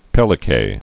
(pĕlĭ-kā)